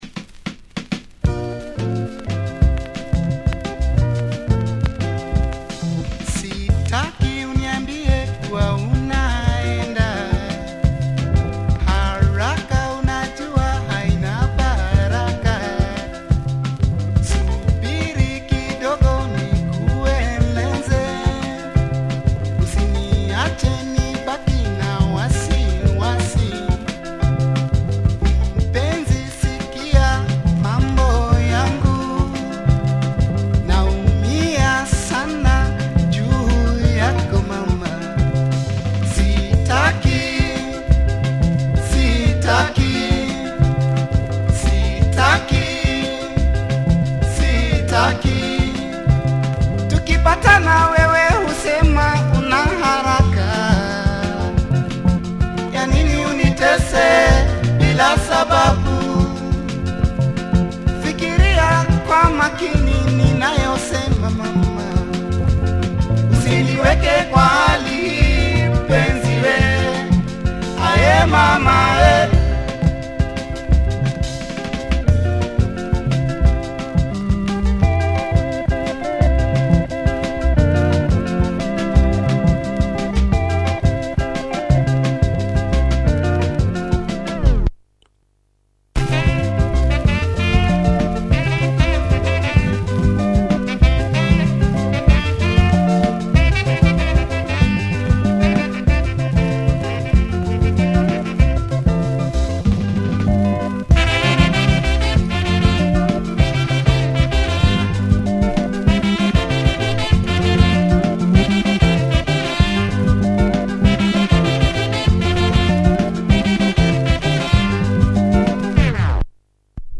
Strickly Afro-pop fashion
sweet vocals
and lush organ.